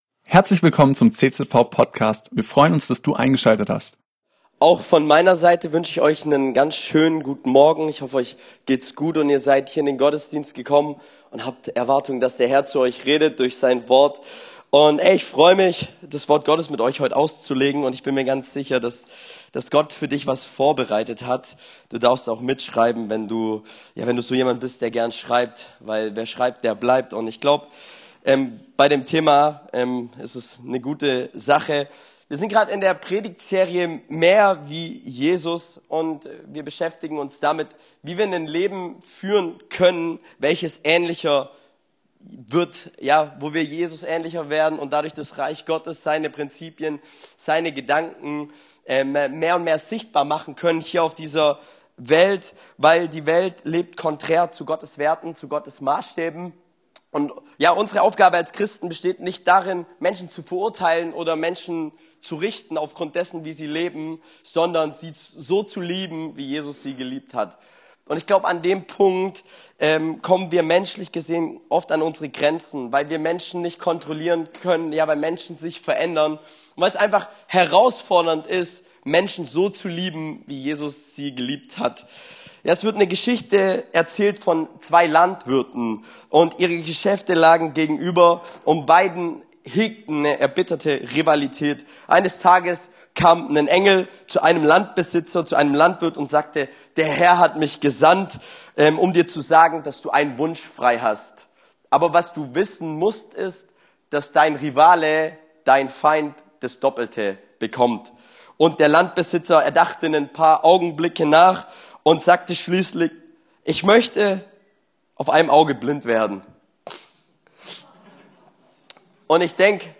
spricht im Rahmen unserer aktuellen Predigtserie zu Matthäus 5, 43-48.